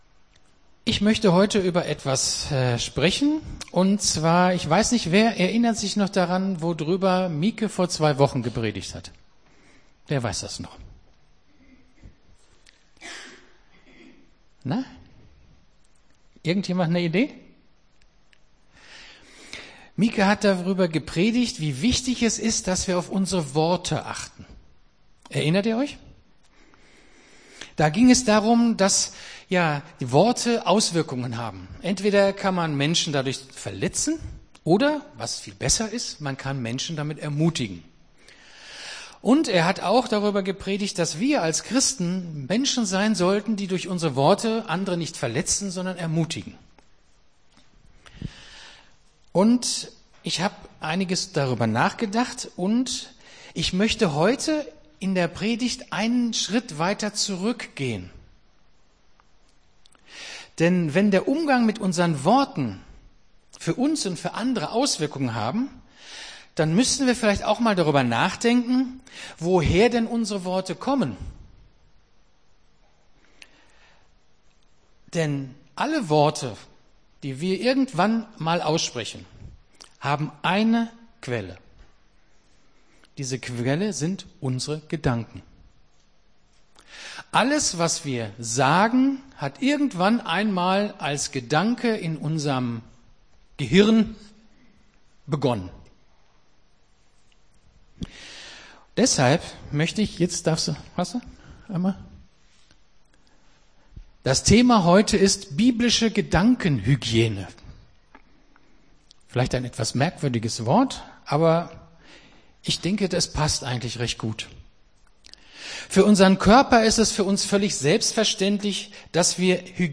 Gottesdienst 30.04.23 - FCG Hagen